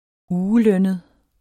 Udtale [ -ˌlœnəð ]